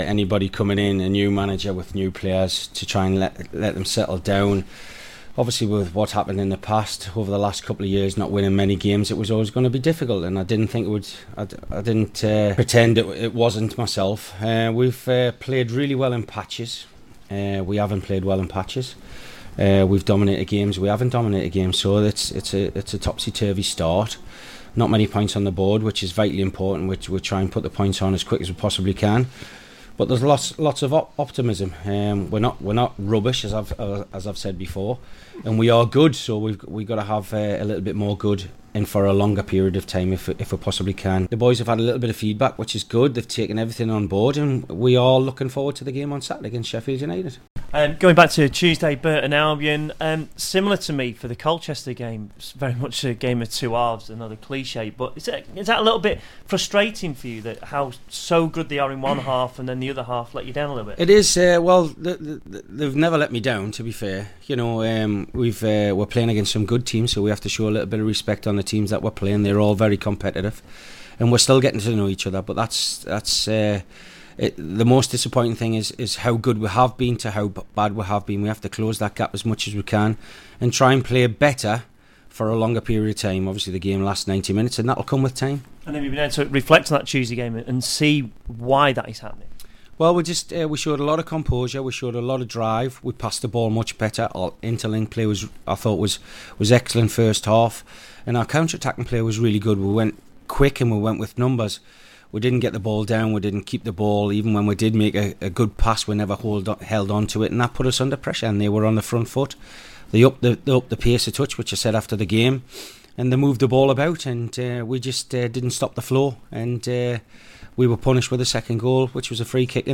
Neil McDonald press conference against Sheffield United